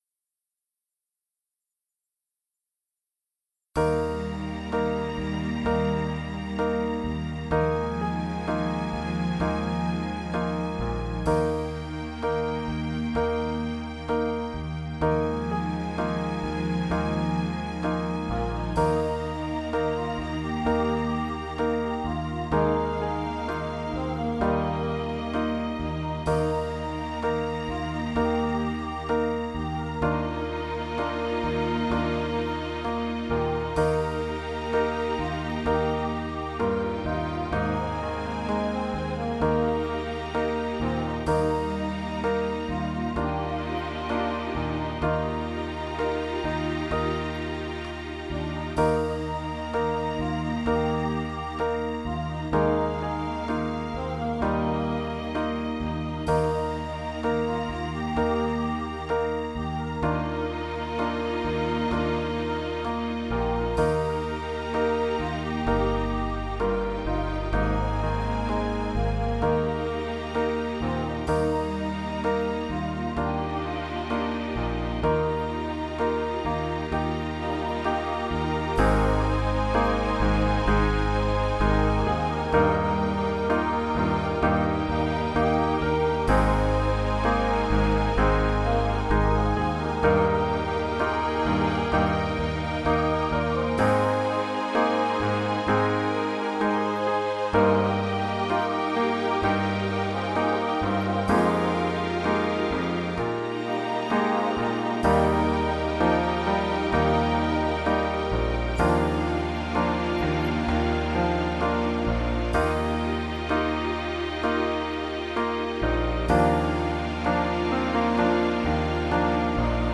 VA = Virtual Accompaniment